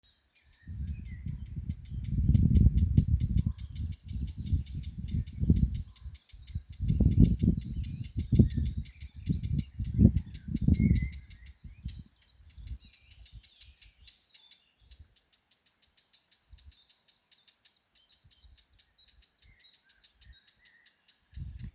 Птицы -> Славковые ->
черноголовая славка, Sylvia atricapilla
СтатусВзволнованное поведение или крики